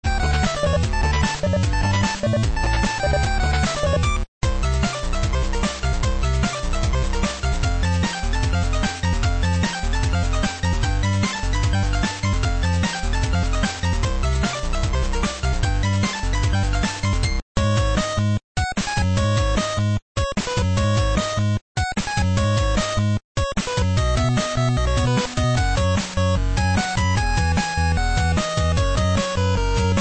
Your one-stop site for Commodore 64 SID chiptune remixes.